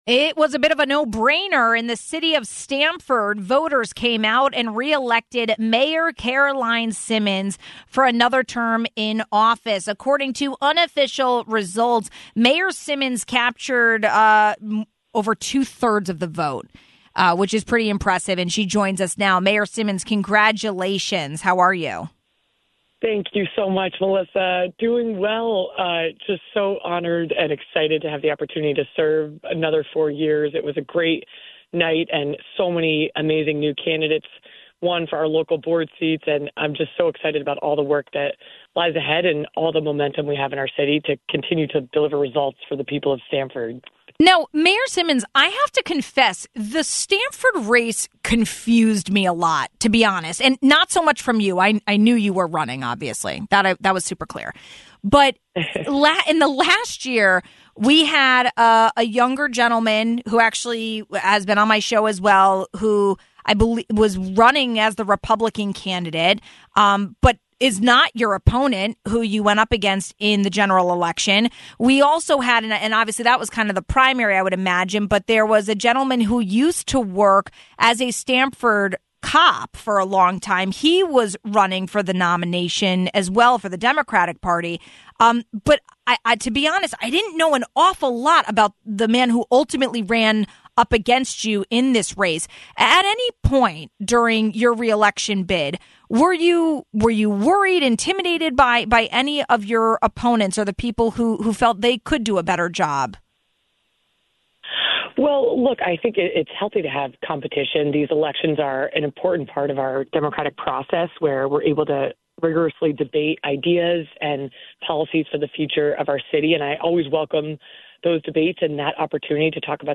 Voters re-elected Mayor Caroline Simmons for another term in office. According to unofficial results, Mayor Simmons captured just over two-thirds of the vote. We spoke with her about her win and continued plans for the city.